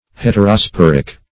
Search Result for " heterosporic" : The Collaborative International Dictionary of English v.0.48: Heterosporic \Het`er*o*spor"ic\, Heterosporous \Het`er*o*spor"ous\, a. [Hetero- + spore.]
heterosporic.mp3